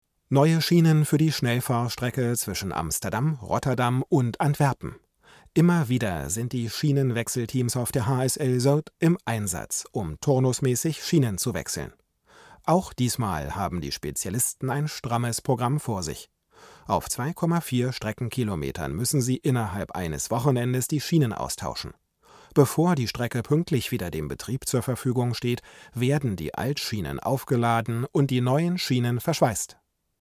Da ist irgendwie alles da und klingt ausgewogen und präzise, ohne zu nerven.